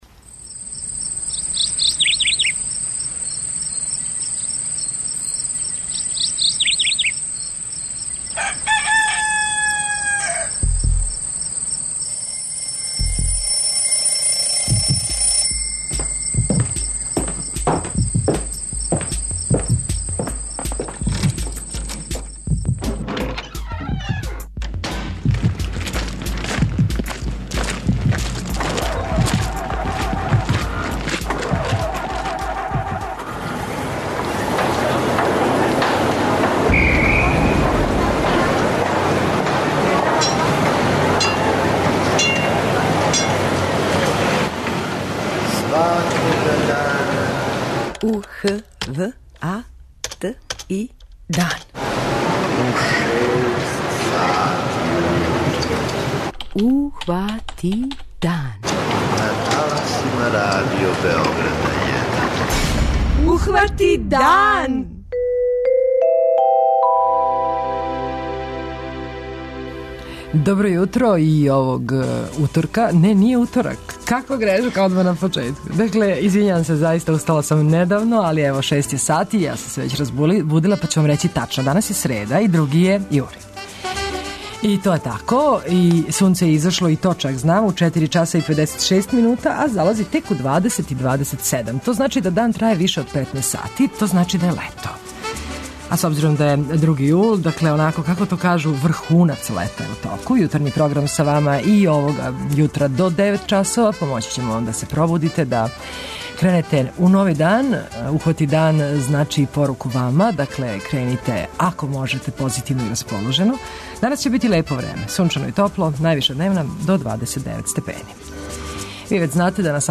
Гости су гимназијалци - учесници пројекта Европски ђачки парламент у Копенхагену и семинара Синулација рада парламента у Скупштини Србије.